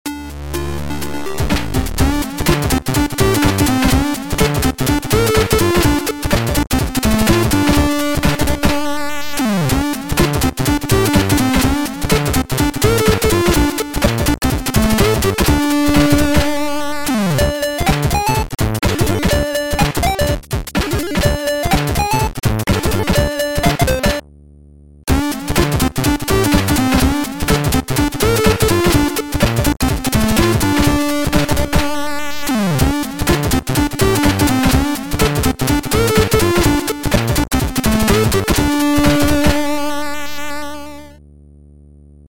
Sound Format: Abyss Highest eXperience